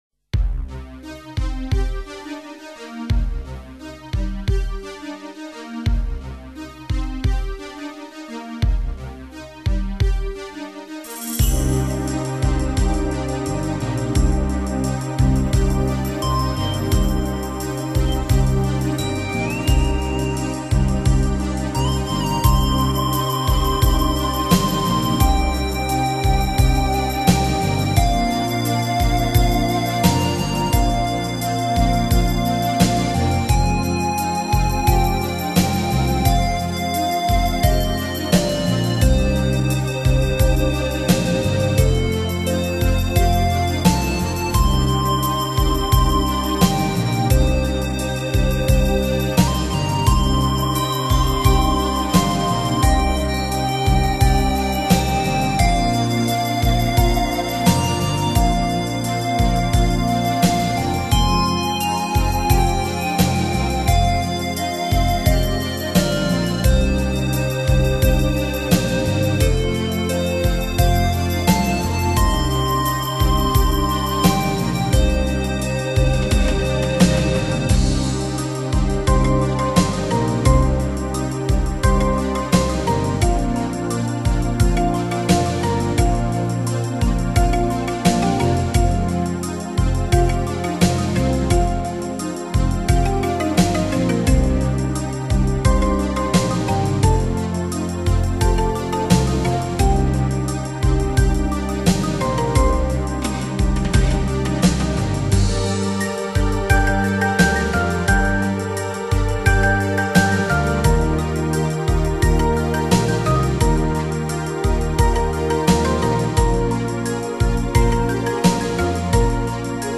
旋律优美，精致无比的乐曲改善心灵状态